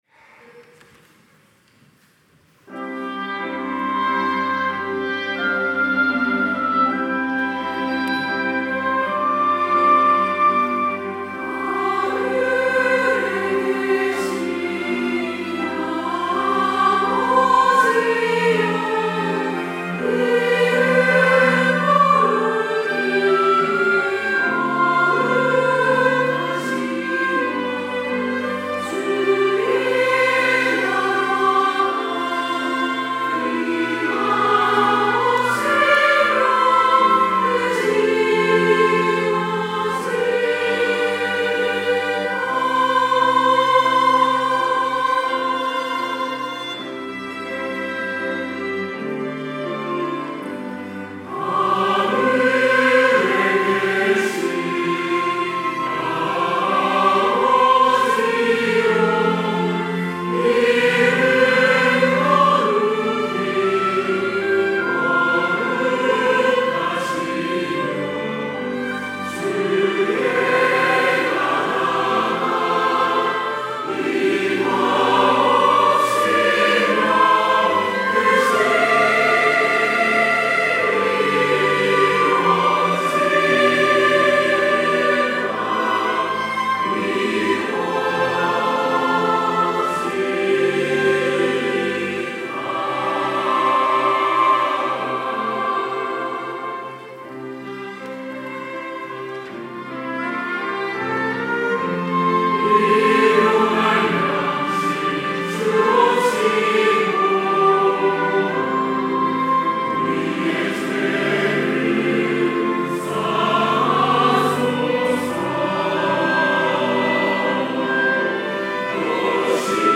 호산나(주일3부) - 주 기도
찬양대